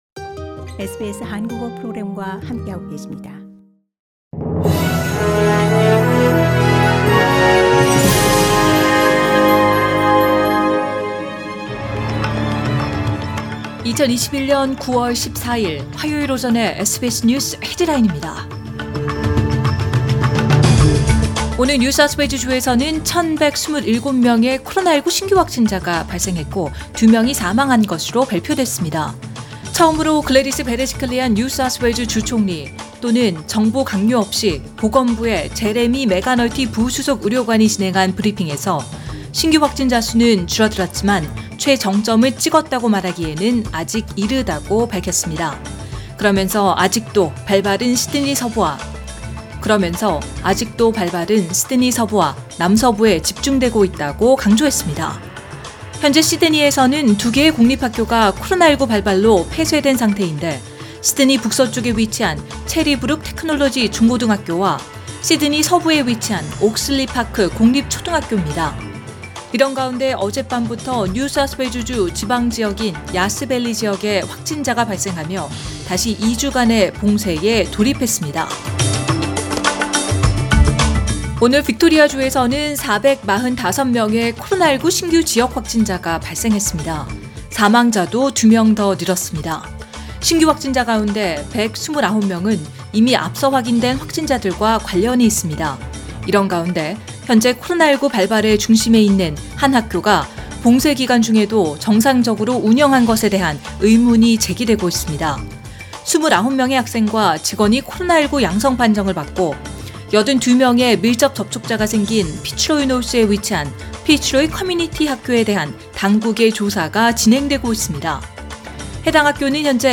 2021년 9월 14일 화요일 오전의 SBS 뉴스 헤드라인입니다.